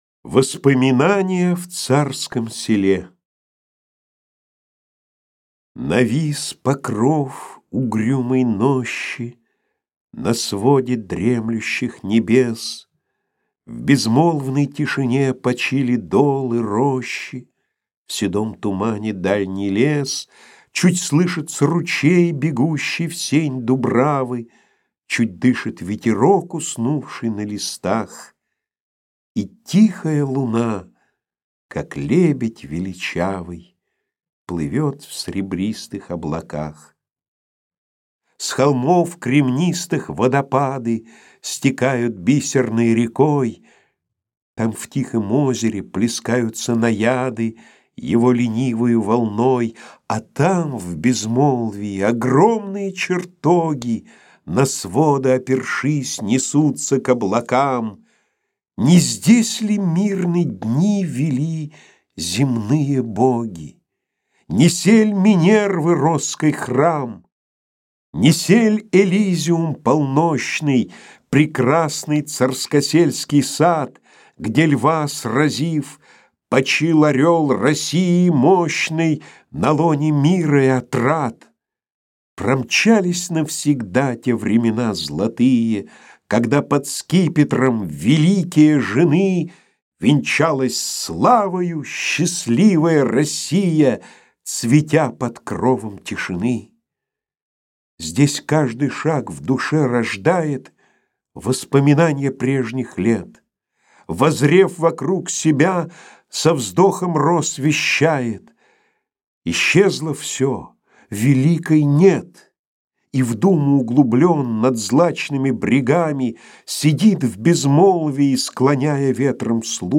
Аудиокнига Я памятник себе воздвиг. Стихотворения | Библиотека аудиокниг